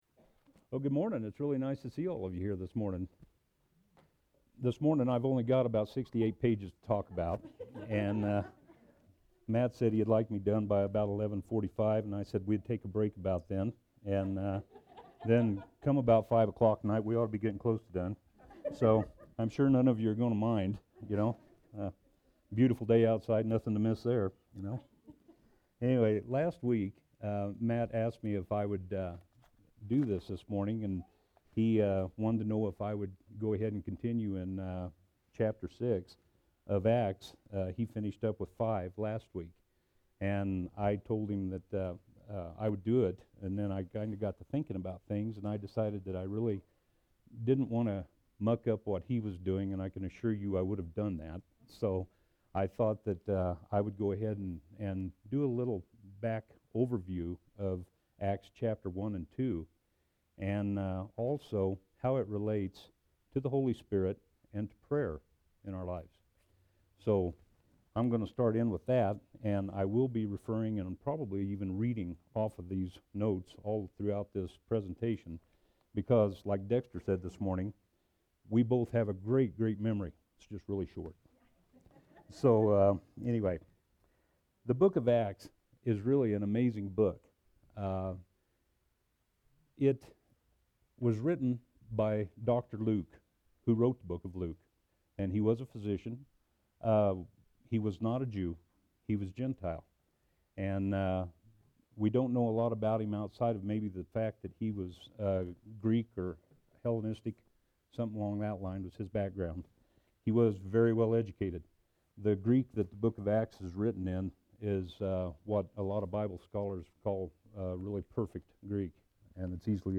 SERMON: Prayer